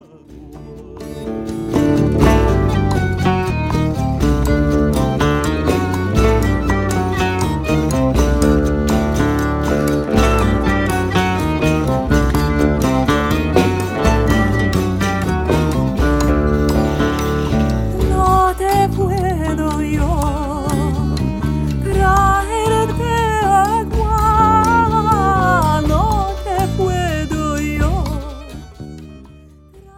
Turkish Sephardic folk song